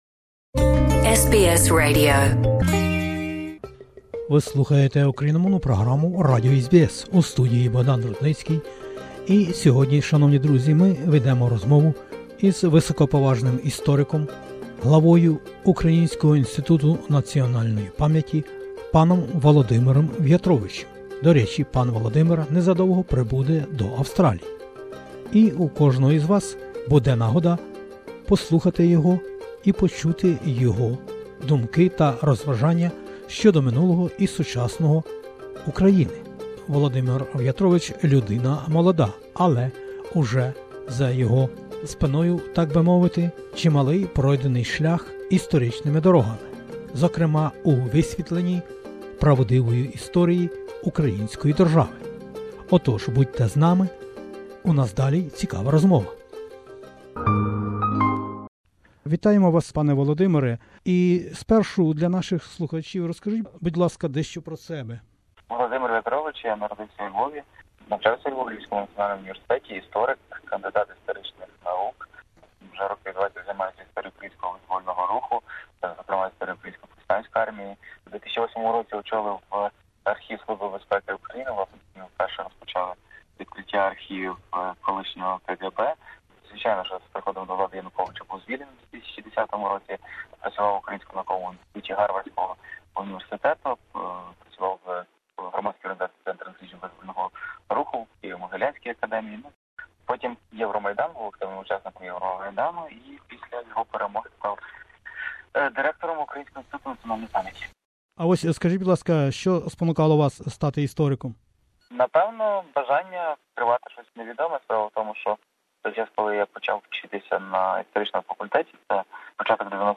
розмову із відомим істориком Володимиром В’ятровичем, директором Українського інституту національної пам’яті.